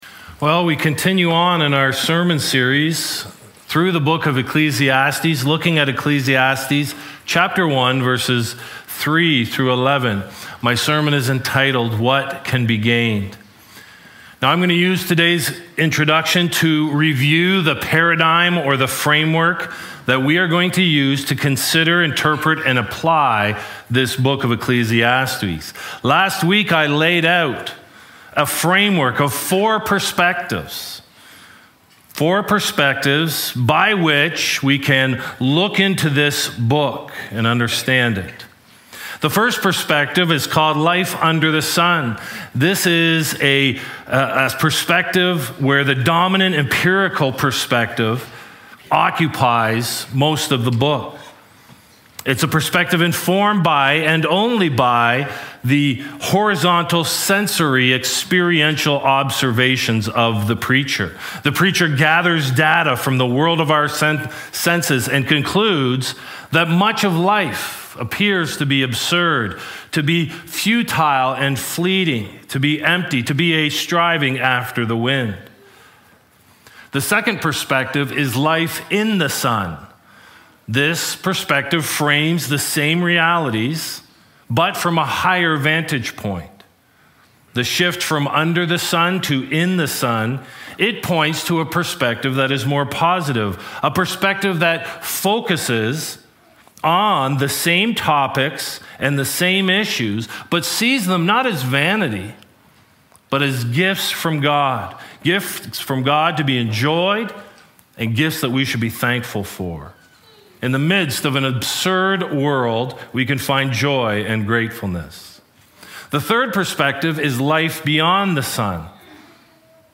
Sermon Archives What Can Be Gained?